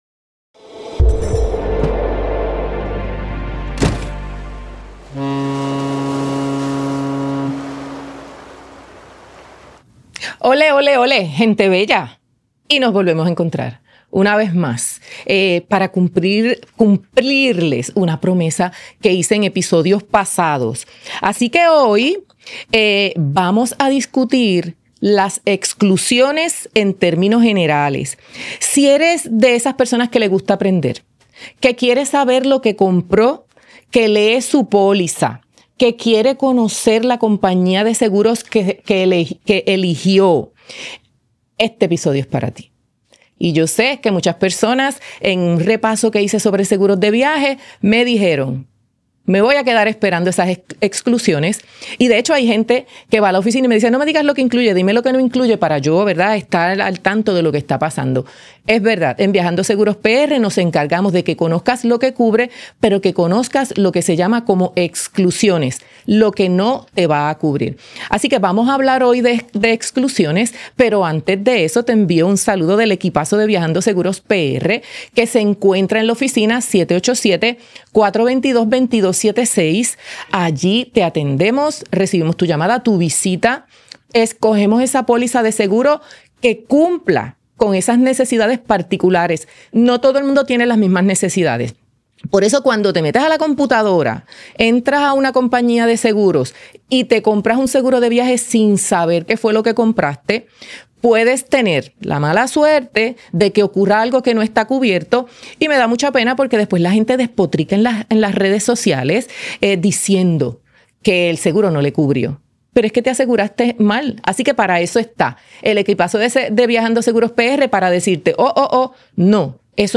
Grabados desde GW5 Studios